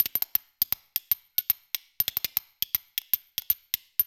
Spoons